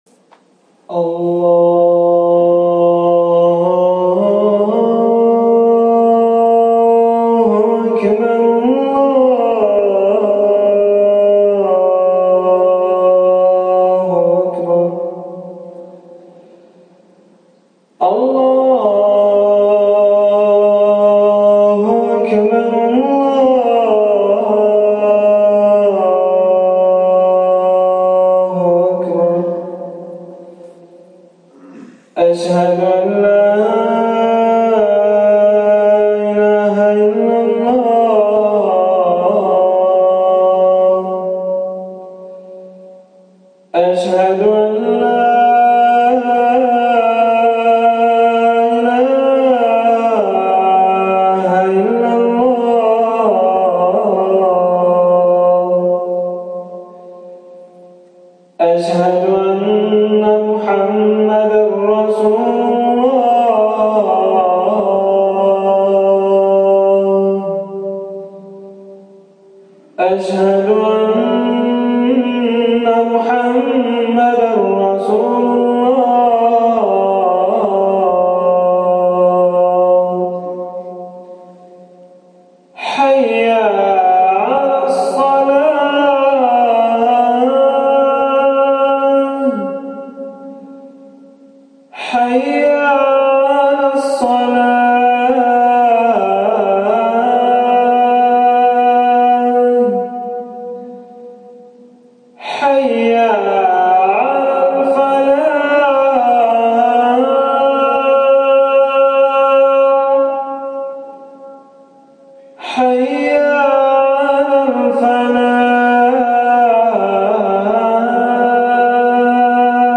아잔 - 오늘의AI위키, AI가 만드는 백과사전
멜로디가 풍부하여 노래처럼 들리는 아잔의 예시